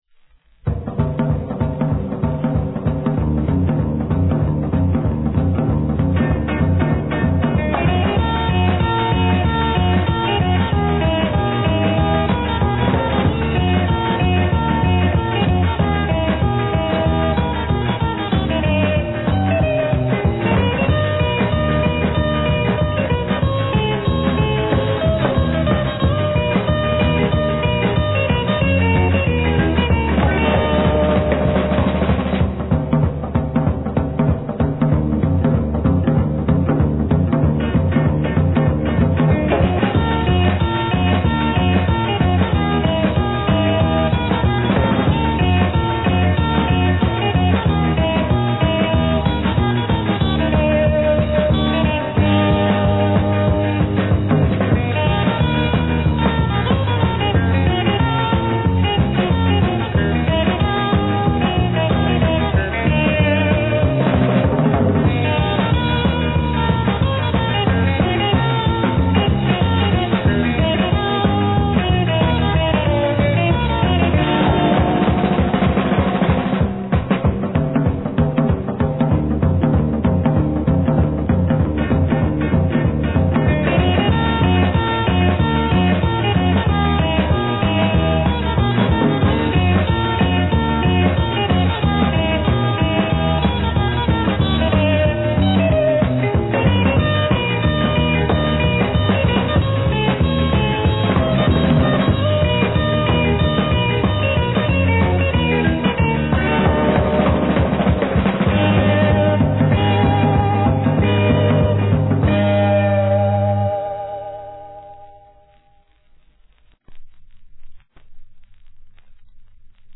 surf music